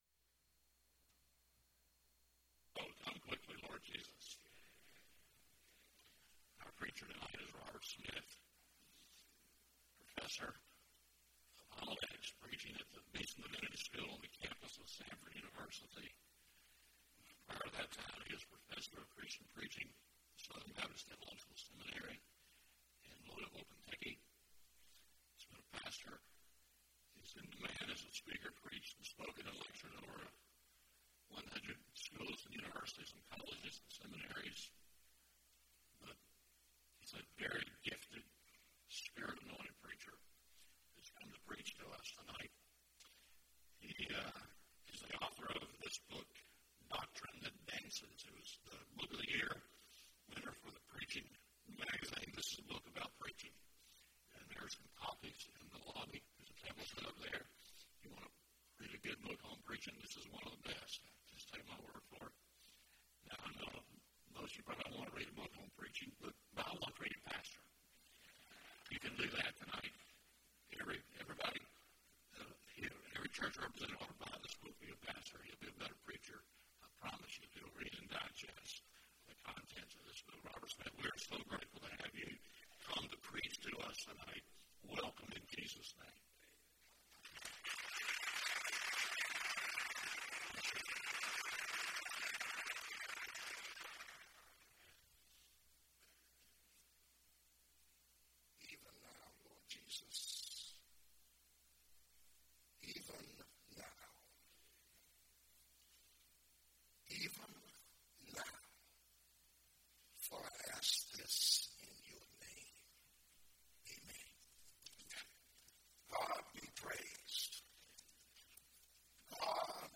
Joint Worship with Auburn Churches
Sermon